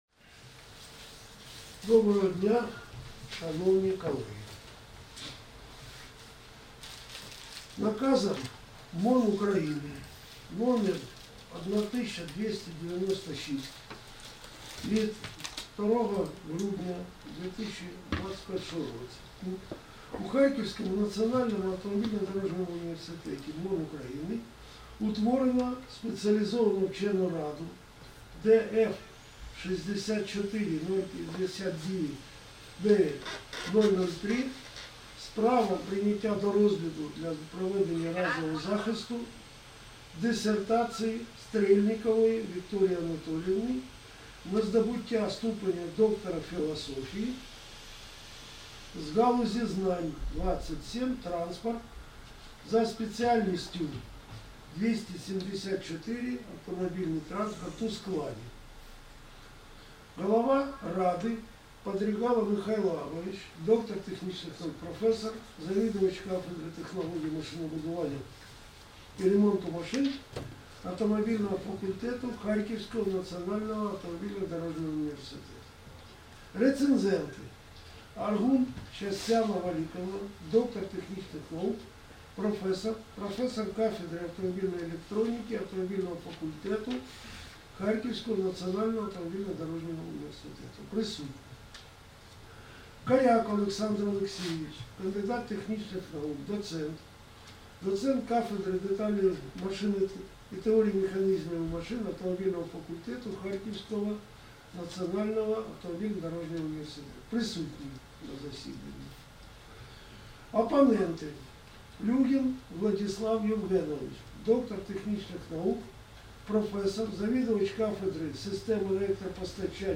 Аудіозапис захисту дисертації на здобуття ступеня доктора філософії (PhD)